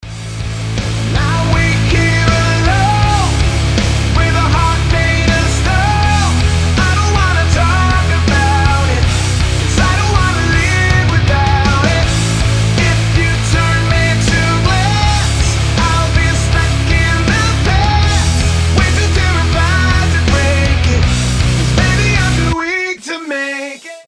• Качество: 320, Stereo
мужской голос
громкие
Драйвовые
Alternative Metal